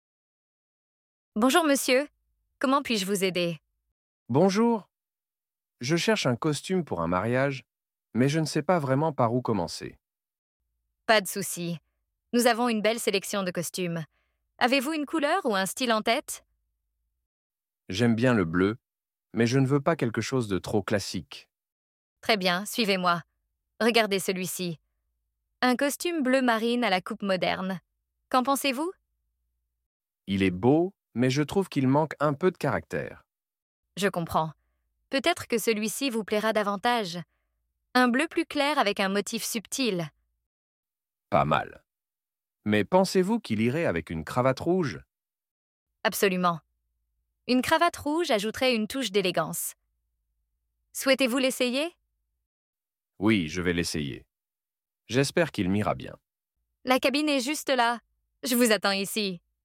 Dialogue : À la boutique